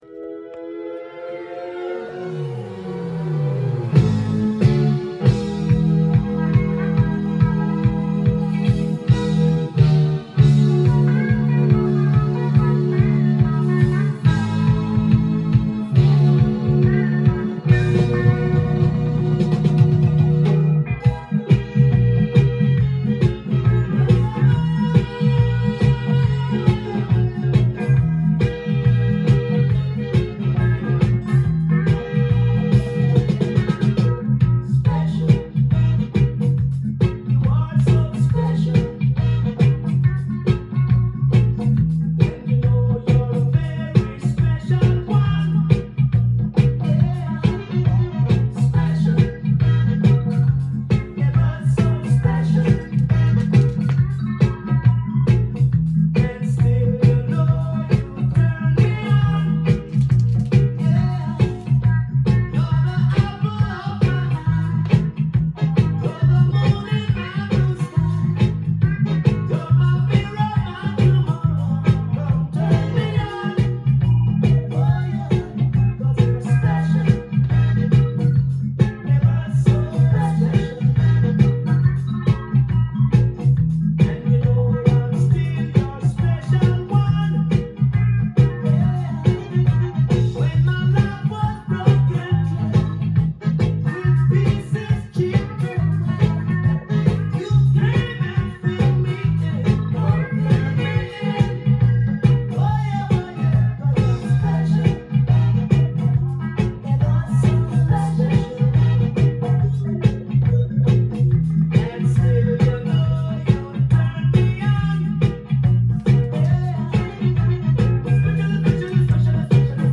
ジャンル：REGGAE
店頭で録音した音源の為、多少の外部音や音質の悪さはございますが、サンプルとしてご視聴ください。